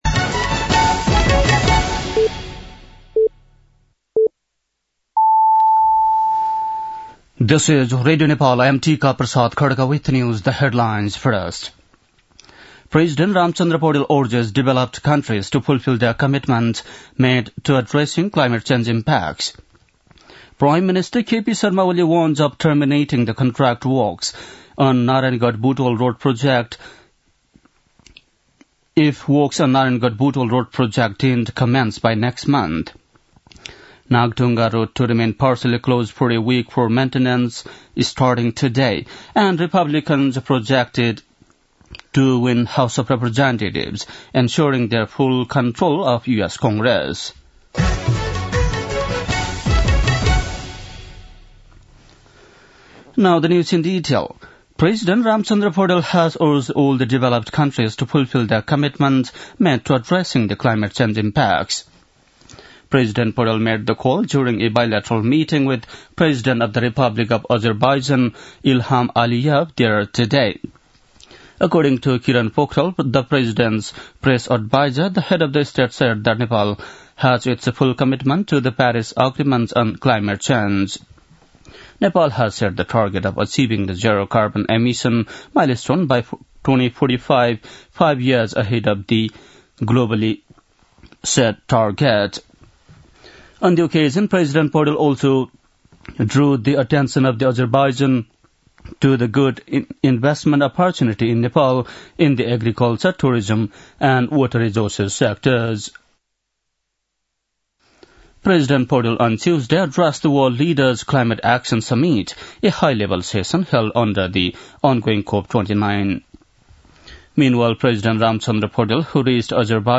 बेलुकी ८ बजेको अङ्ग्रेजी समाचार : ३० कार्तिक , २०८१
8-pm-english-news-7-29.mp3